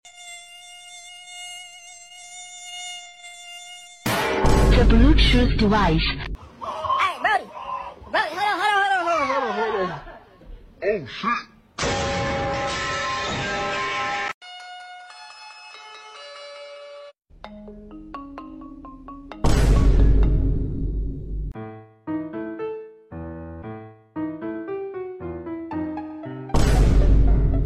🤣 Ranking The Funniest GTA Sound Effects Free Download